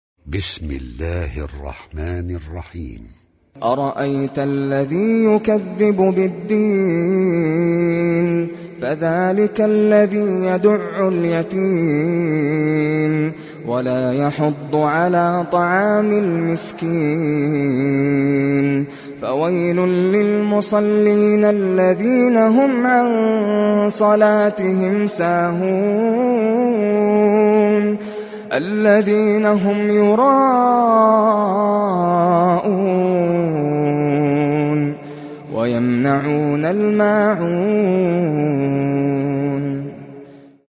Sourate Al Maun mp3 Télécharger Nasser Al Qatami (Riwayat Hafs)